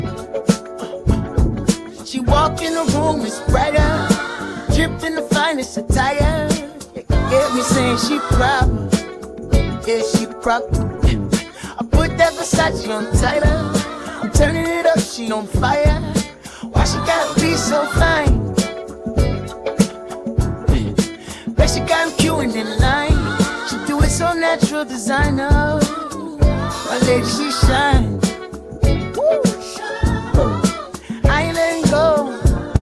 An incredible R&B/Funk song, creative and inspiring.